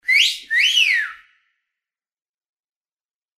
» свист Размер: 56 кб